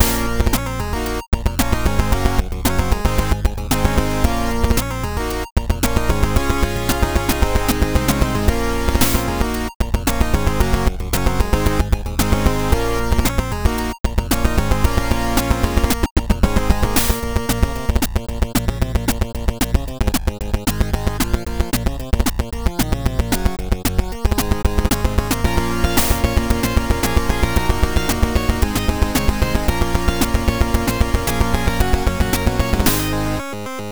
Retro version with MIDI file included.